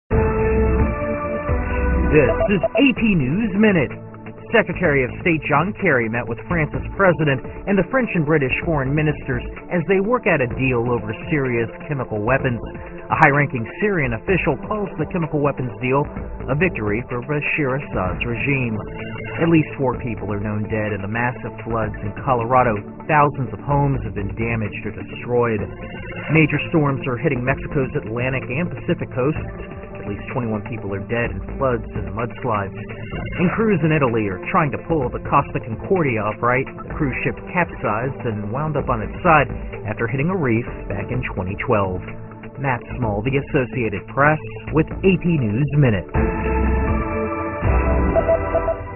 在线英语听力室美联社新闻一分钟 AP 2013-09-21的听力文件下载,美联社新闻一分钟2013,英语听力,英语新闻,英语MP3 由美联社编辑的一分钟国际电视新闻，报道每天发生的重大国际事件。电视新闻片长一分钟，一般包括五个小段，简明扼要，语言规范，便于大家快速了解世界大事。